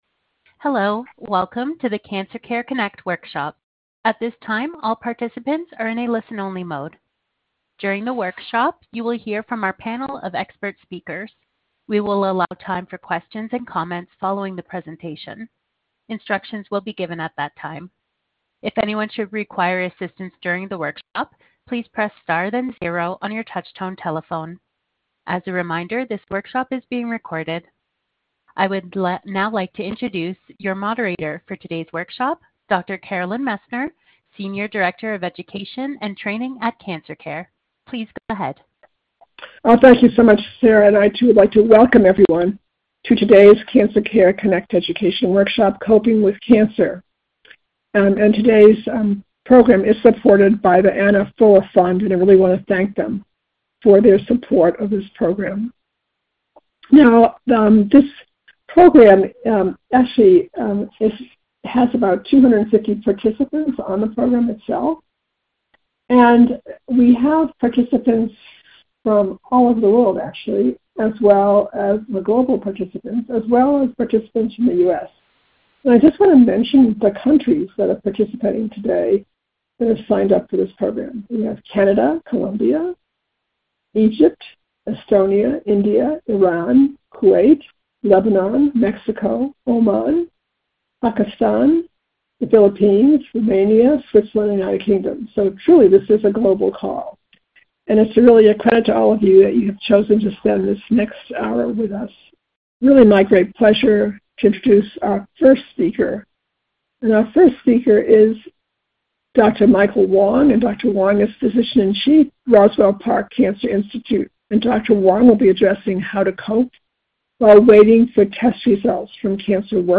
Questions for Our Panel of Experts
This workshop was originally recorded on March 12, 2025.